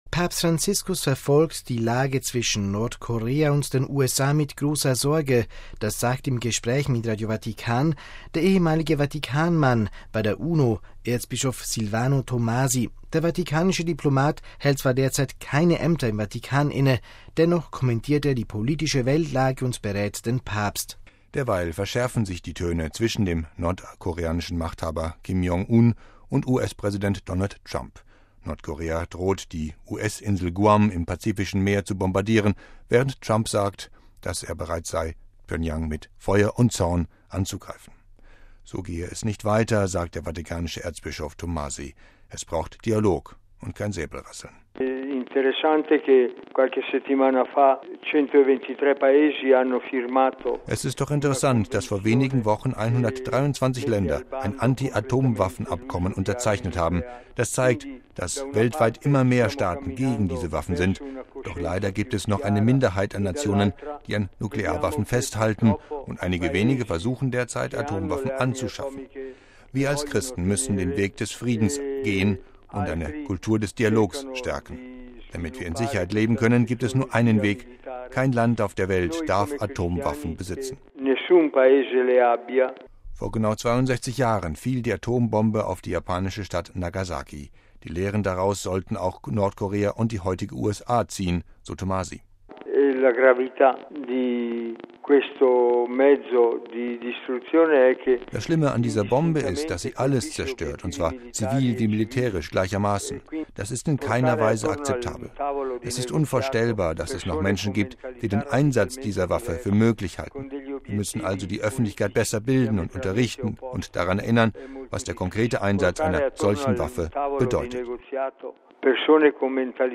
Papst Franziskus verfolgt die Lage zwischen Nordkorea und den USA mit großer Sorge. Das sagt im Gespräch mit Radio Vatikan der ehemalige Vatikanmann bei der UNO, Erzbischof Silvano Tomasi.